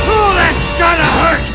hurt.mp3